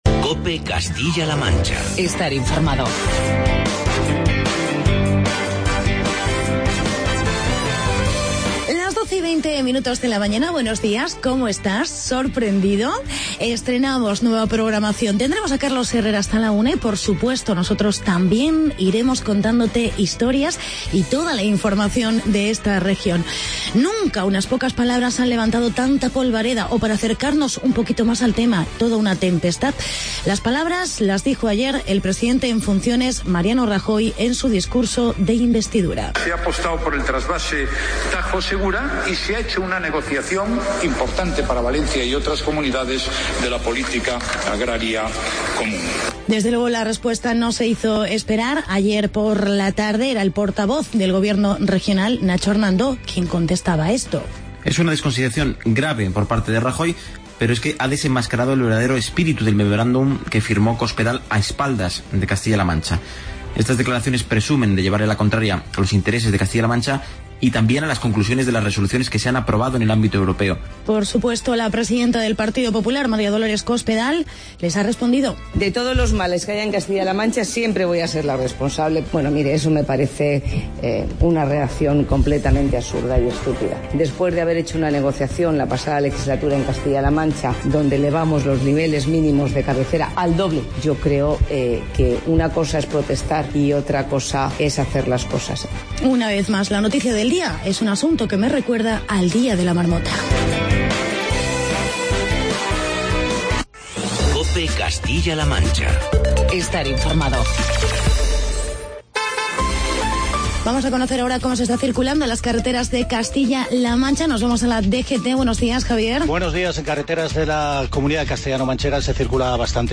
Escuchamos las reacciones a las palabras de Mariano Rajoy en la sesión de investidura sobre el Trasvase Tajo-Segura. Entrevistamos a Amaral, uno de los grupos participantes del Festival Gigante de Guadalajara.